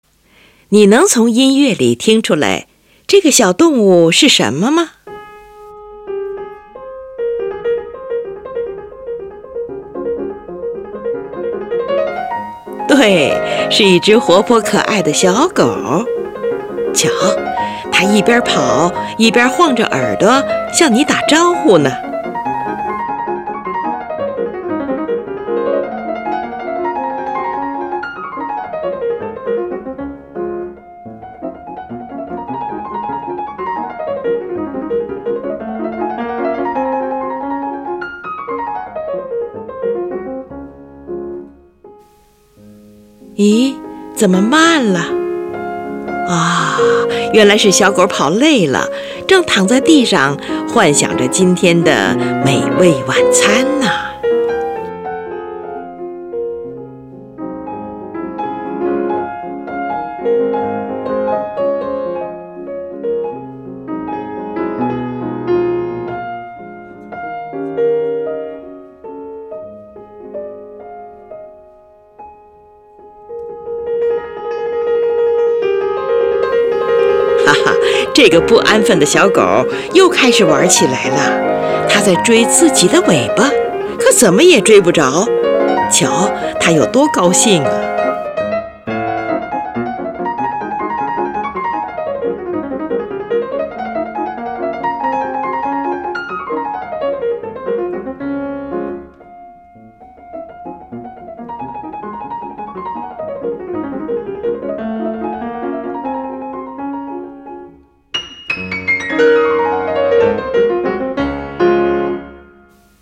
降D大调圆舞曲（“小狗”）（作品64之1）作品64号共有三首圆舞曲，是肖邦在世时最后发表的圆舞曲。
曲调健康活泼，诙谐有趣，把小狗的神态活现在听众面前。中间一段是优美抒情的圆舞曲主题，好像小狗奔跑了一段时间，躺下来休息片刻，悠然自得，懒散舒适。第三段又是快速的音型，就像小狗休息片刻以后，又开始追逐尾巴的游戏。
只用一分钟多一点的时间，给人非常轻松愉快的感觉。